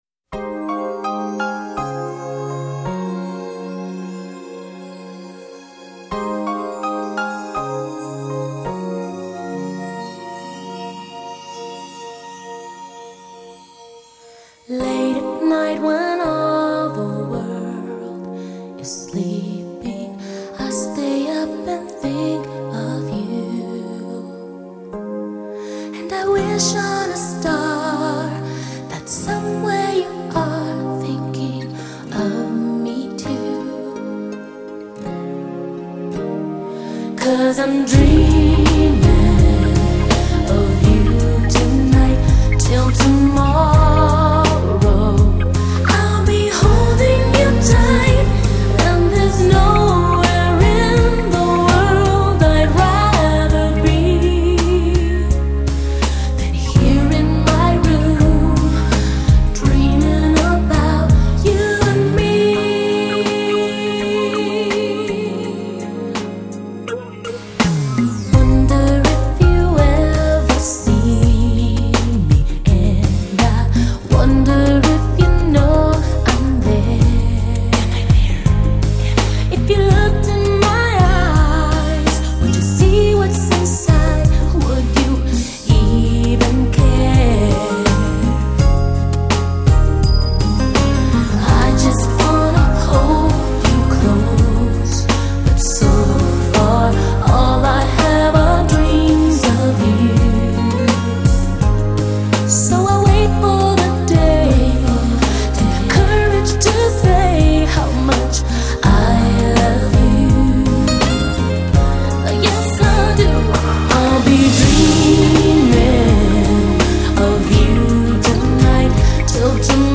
texmex